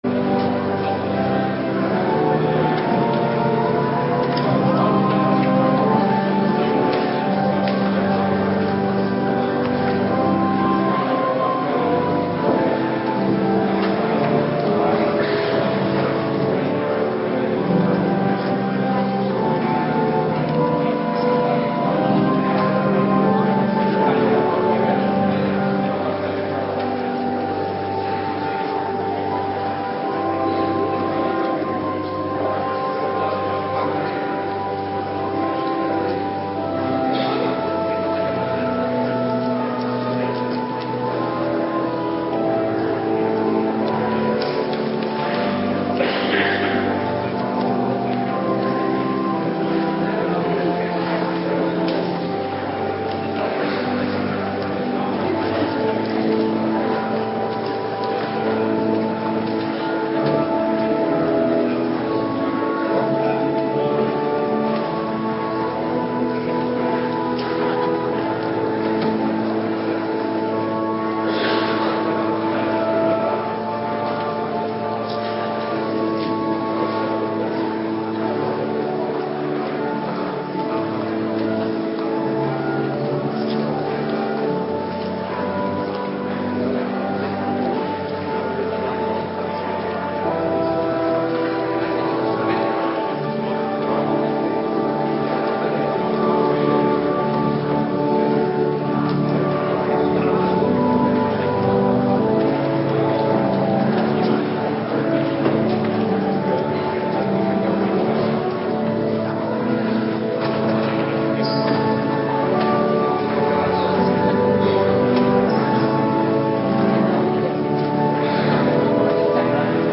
Ps 33 . 11 Ps 103 . 2 Schriftlezing Psalm 121 Ps 121 . 1 en 2 Ps 121 . 3 en 4 Ps 72 . 7 en 11 Thema: Een Naam voor onderweg